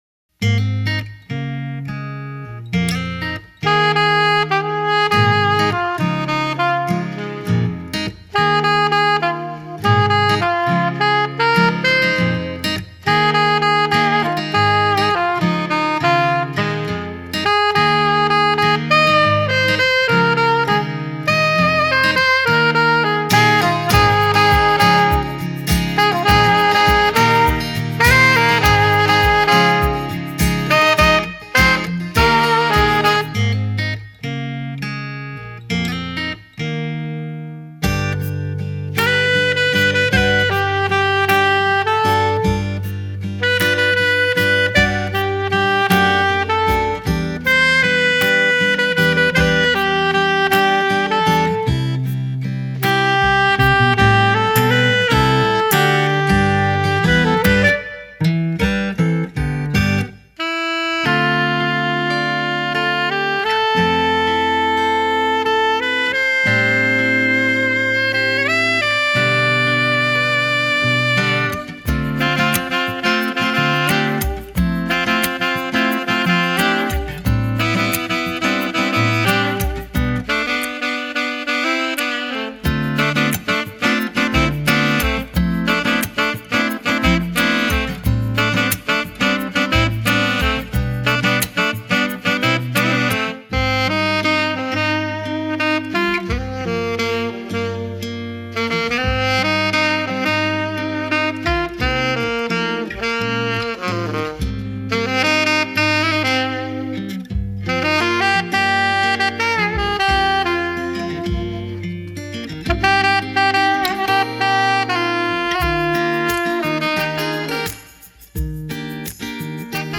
Smart and sophisticated solo saxophonist for hire.
• Unique arrangements of iconic pop songs
• Sax
Saxophonist accompanied with Professional Backing Tracks